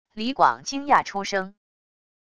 李广惊讶出声wav音频